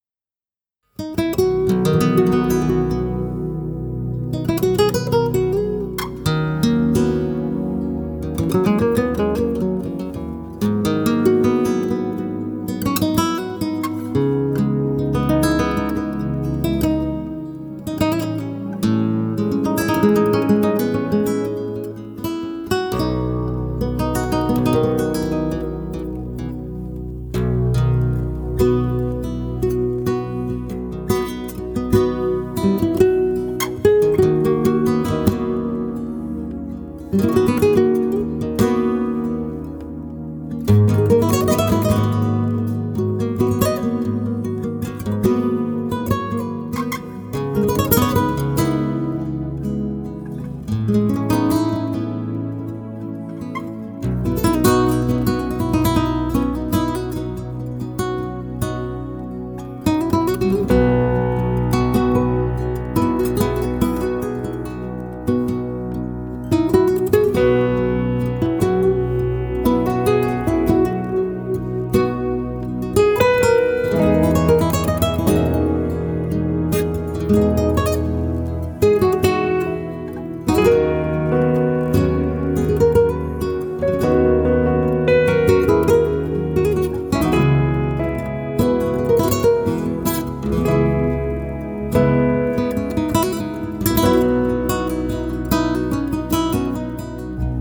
Zurück zu: Flamenco